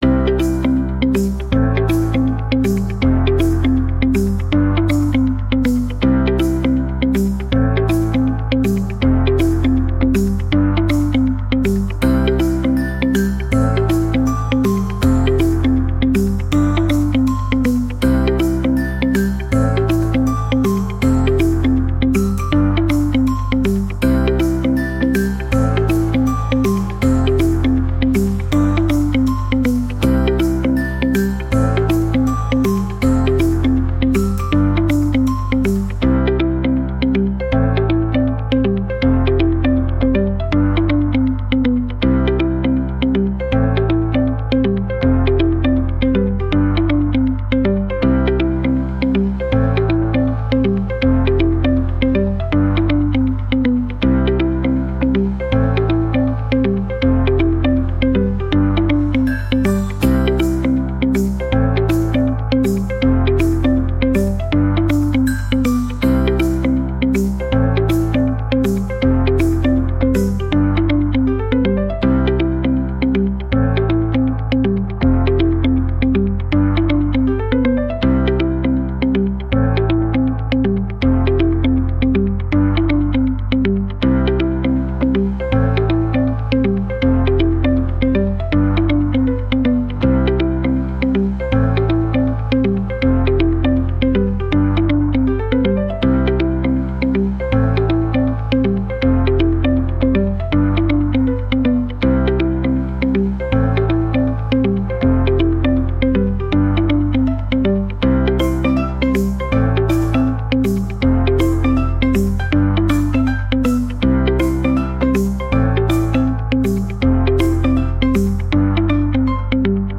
🎶 Детские песни / Музыка детям 🎵